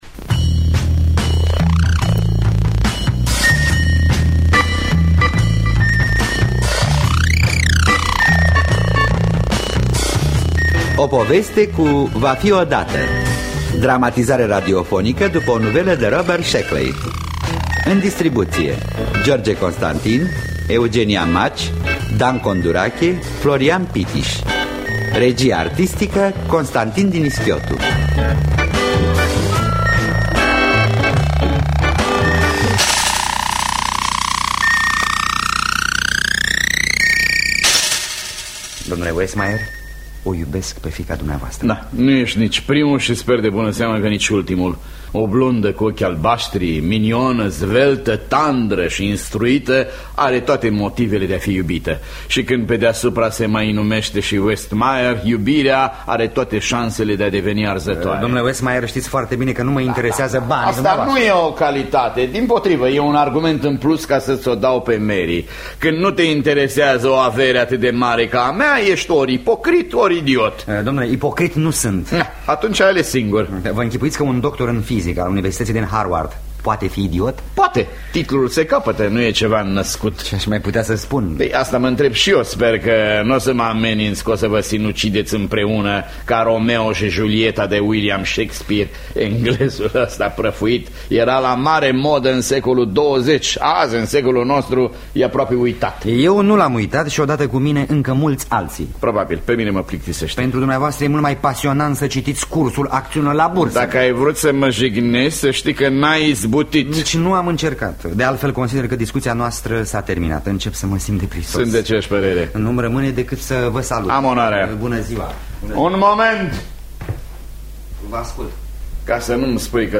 O poveste cu… va fi odată de Robert Shekley – Teatru Radiofonic Online